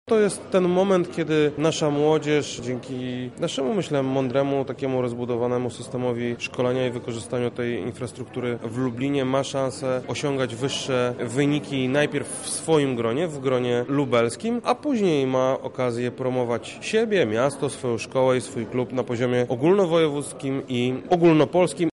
• mówi Krzysztof Komorski, Zastępca Prezydenta ds. Kultury, Sportu i Partycypacji.